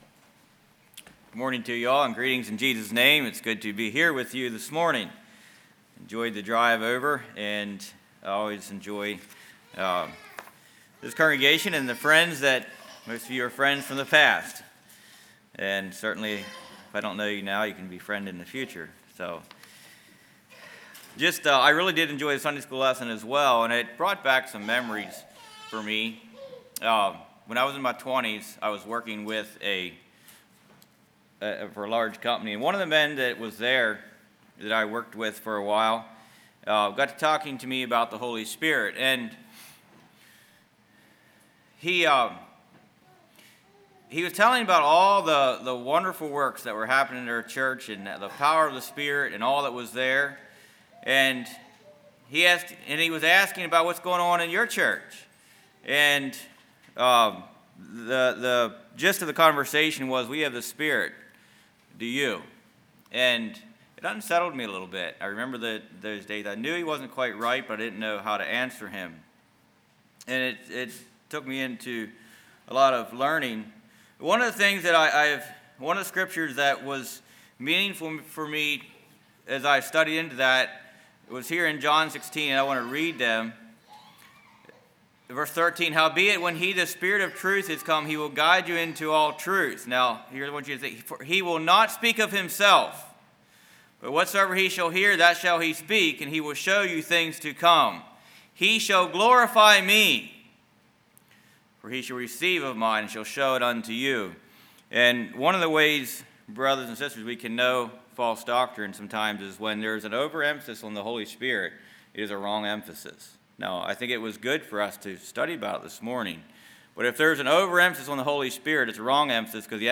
Play Now Download to Device Freedom in the Yoke Congregation: Keysville Speaker